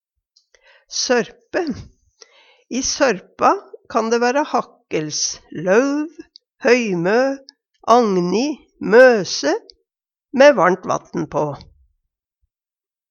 sørpe - Numedalsmål (en-US)